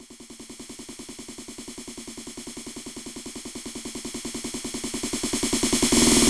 drums03.wav